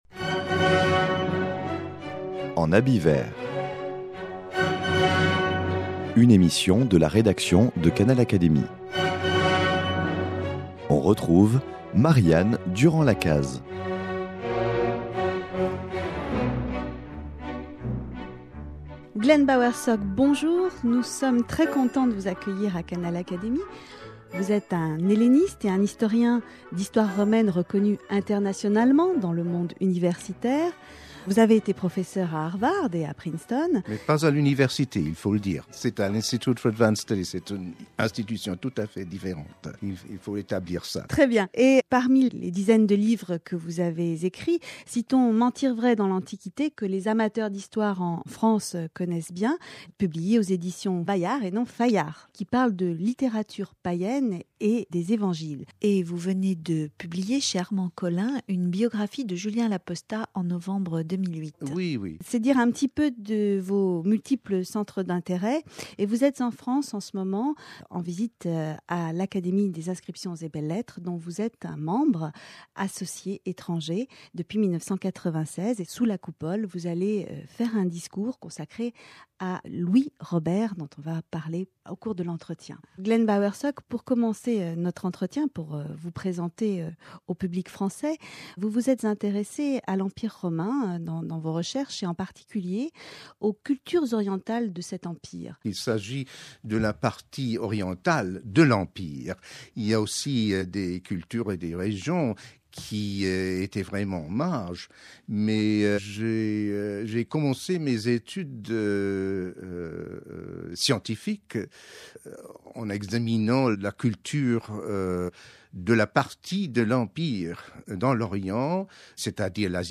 Écoutez l’interview de cet historien et philologue de renommée mondiale.